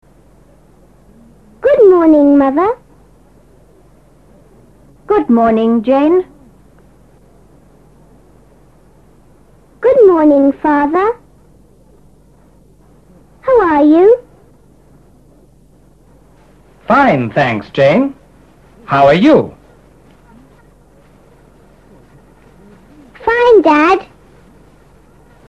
A continuación, escucha atentamente esta mini-conversación entre Jane y sus padres.